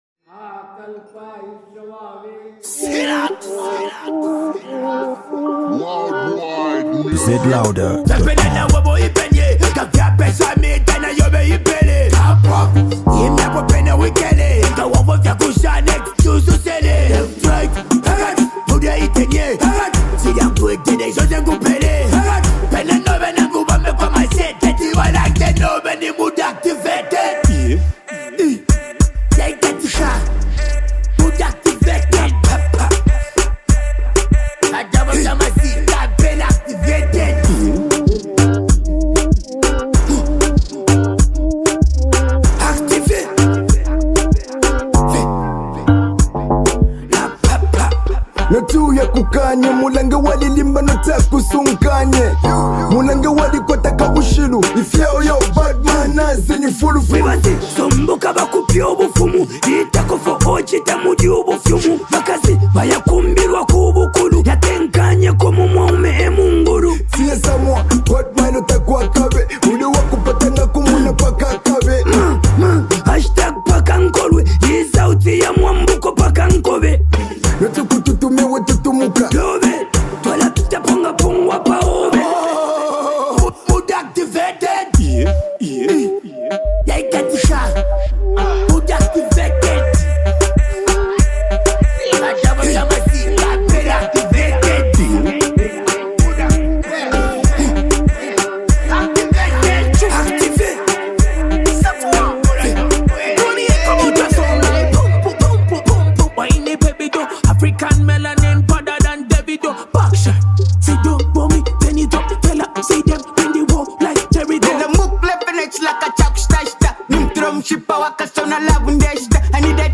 promising raper